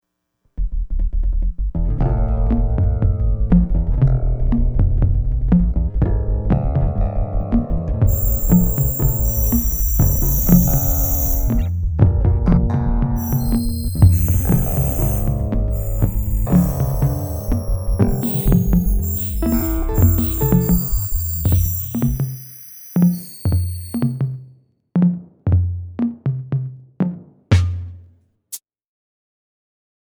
Filter 2 Disjointed and exotic